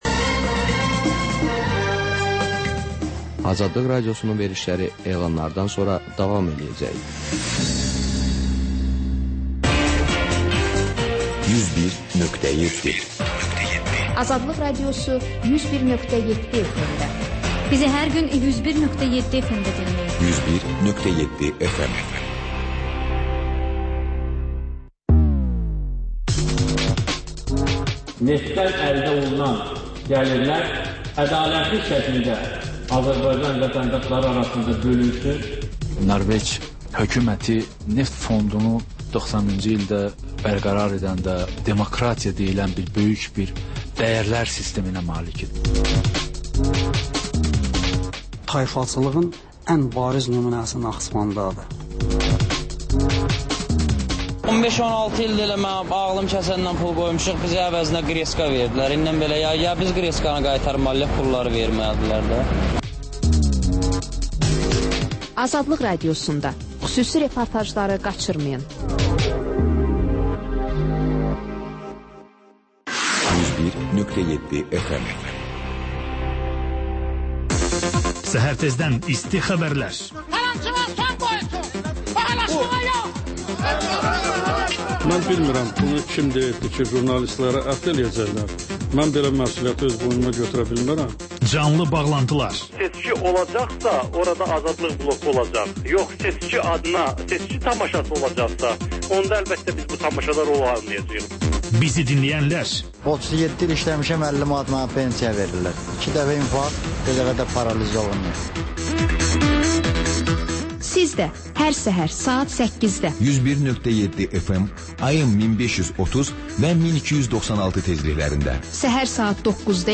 Həftə boyu efirə getmiş CAN BAKI radioşoularında ən maraqlı məqamlardan hazırlanmış xüsusi buraxılış (TƏKRAR)